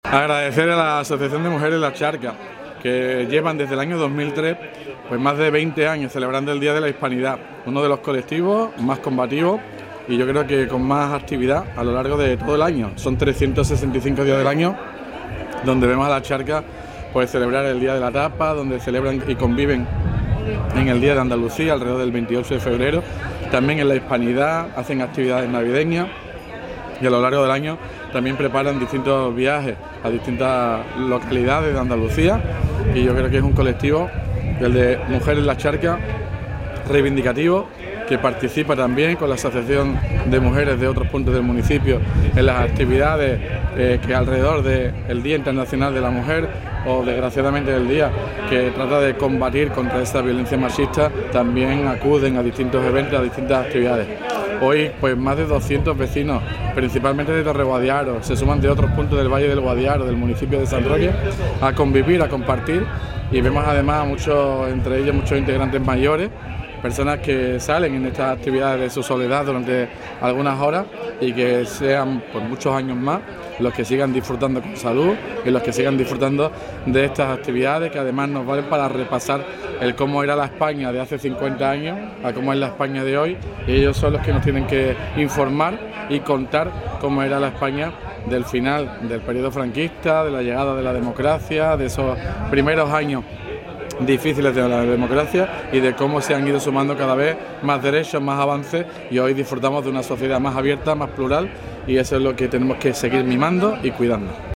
FIESTA_CHARCA_TOTAL_ALCALDE.mp3